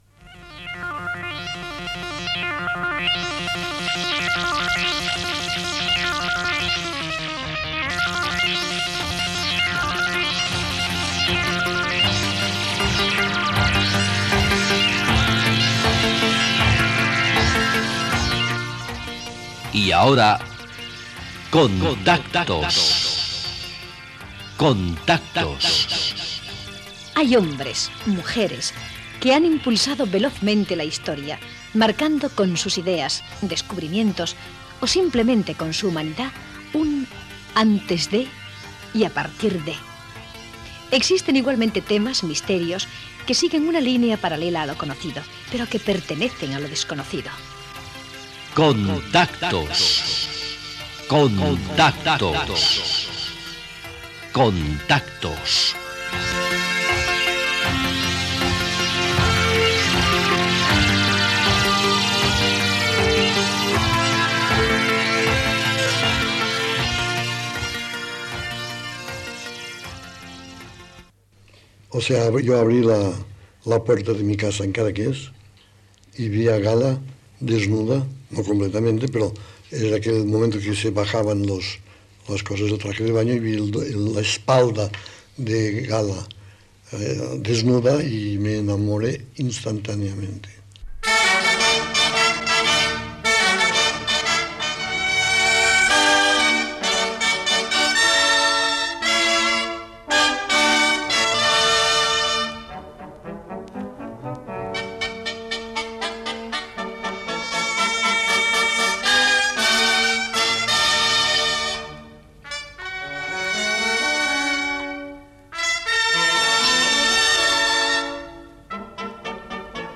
Careta del programa. Semblança i entrevista al pintor Salvador Dalí.